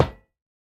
sounds / block / iron / break8.ogg
break8.ogg